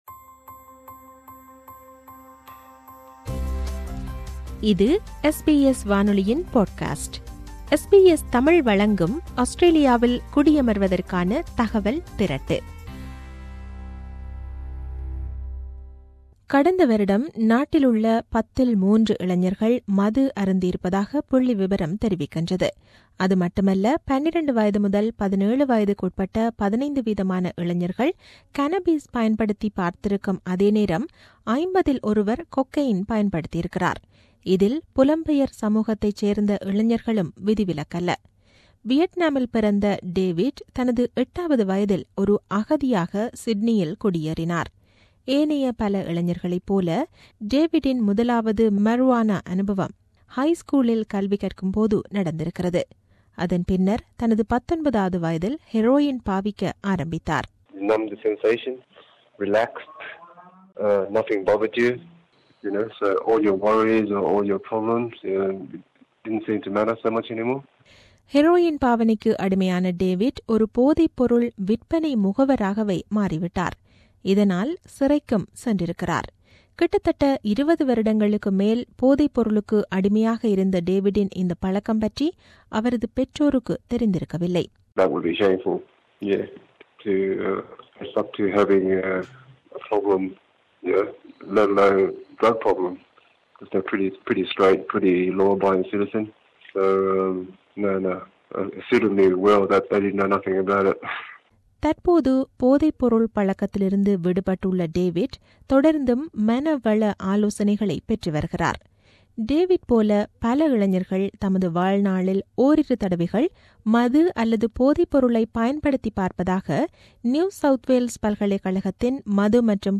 கடந்த வருடம் நாட்டிலுள்ள பத்தில் 3 இளைஞர்கள் மது அருந்தியிருப்பதாக புள்ளி விபரம் தெரிவிக்கின்றது. இதில் புலம்பெயர் சமூகத்தைச் சேர்ந்த இளைஞர்களும் விதிவிலக்கல்ல. போதைப் பழக்கத்தை ஆரம்பத்திலேயே அடையாளம் காண்பது குறித்த செய்தி விவரணம்.